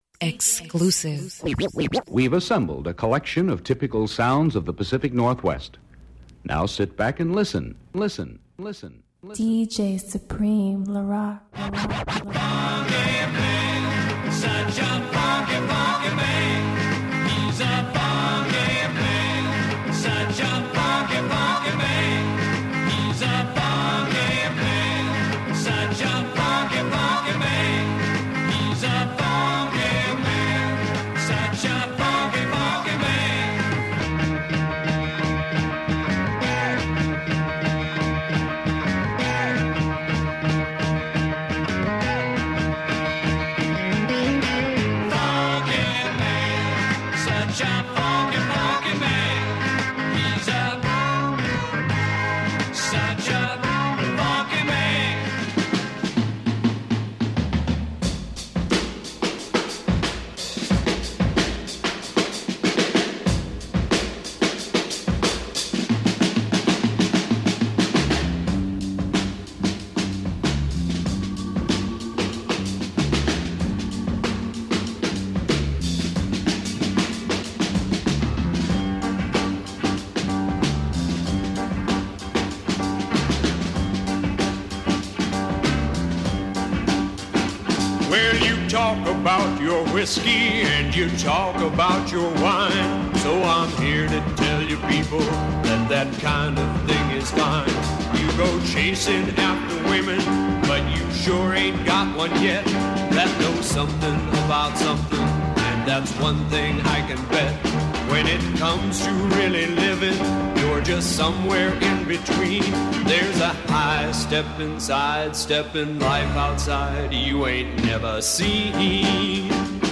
Funk/Soul